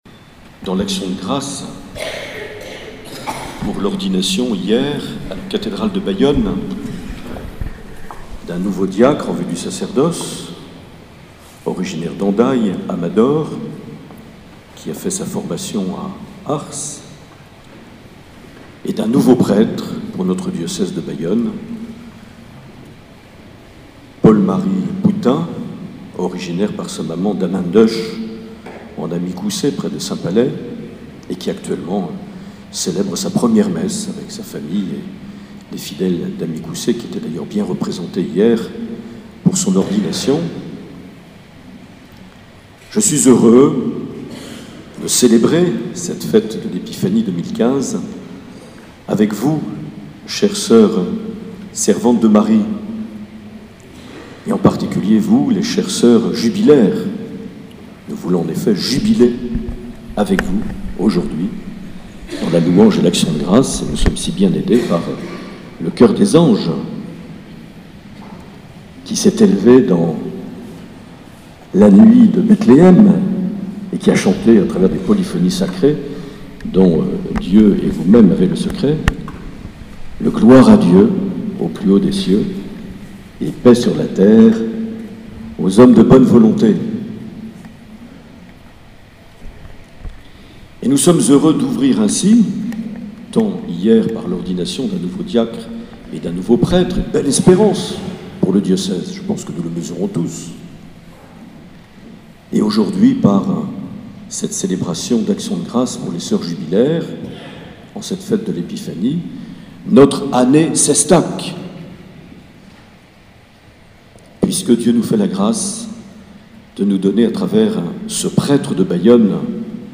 4 janvier 2015 - Notre Dame du Refuge Anglet - Lancement de l’année Louis-Édouard Cestac
Accueil \ Emissions \ Vie de l’Eglise \ Evêque \ Les Homélies \ 4 janvier 2015 - Notre Dame du Refuge Anglet - Lancement de l’année (...)
Une émission présentée par Monseigneur Marc Aillet